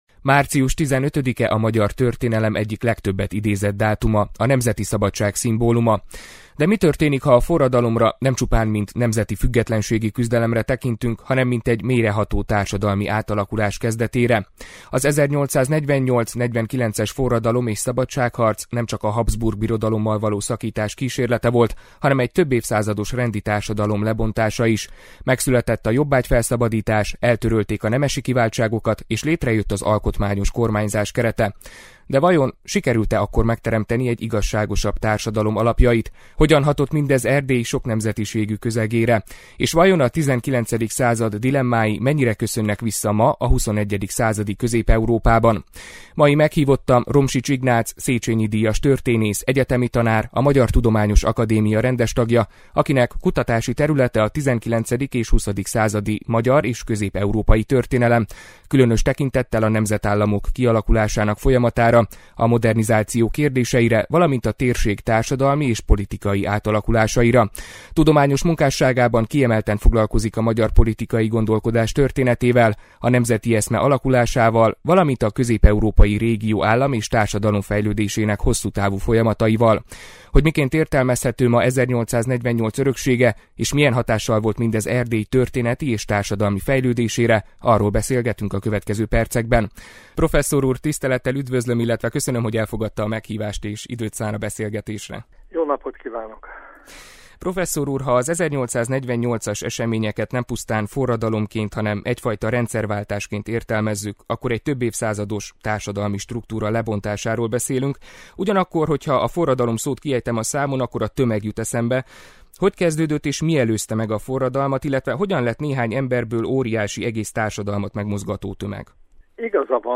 Mit jelent ma 1848 öröksége? – Beszélgetés Romsics Ignác történésszel